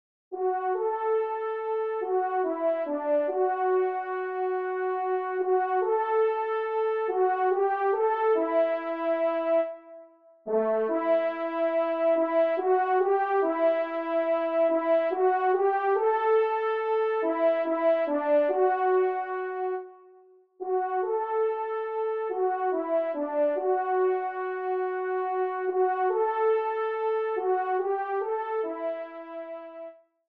Genre :  Musique religieuse pour Trompes ou Cors
1 et 2ème Trompes